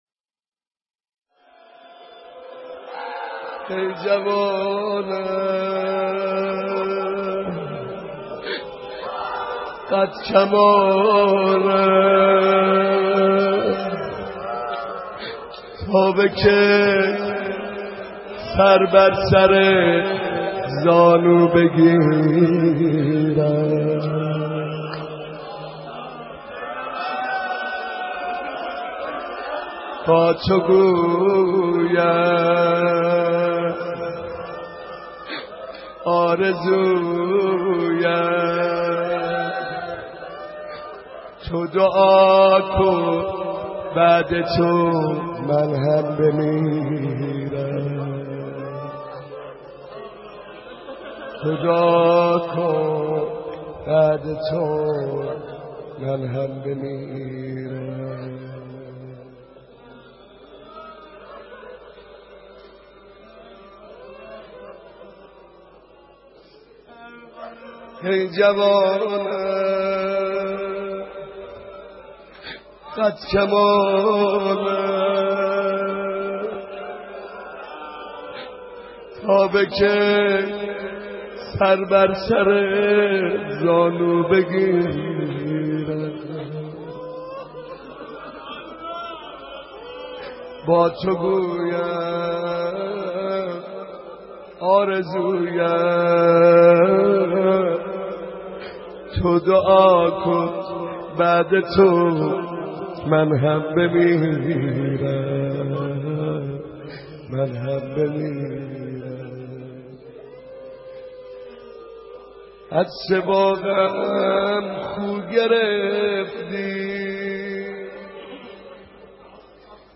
نوحه سرایی در شهادت حضرت فاطمه(س